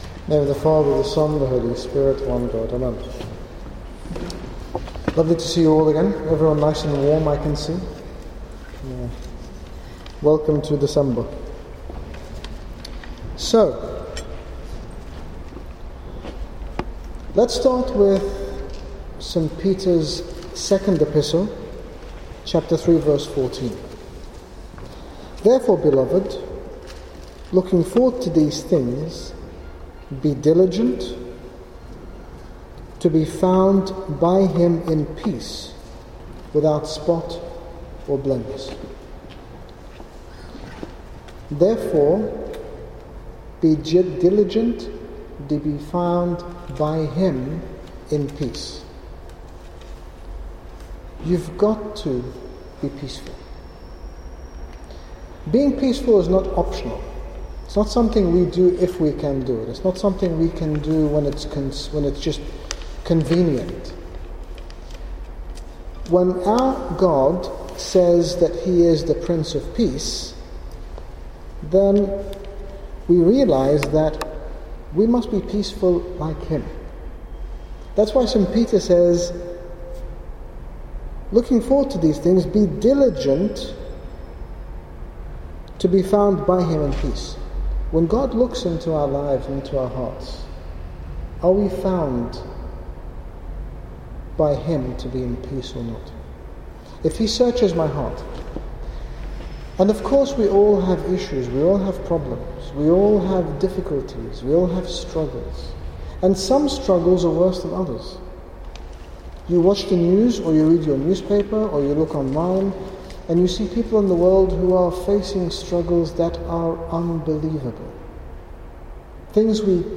In this talk His Grace Bishop Angaelos, General Bishop of the Coptic Orthodox Church in the United Kingdom speaks about being truly at peace by living in the way God intended for us all to live and by placing value in the everlasting and not merely in the temporal.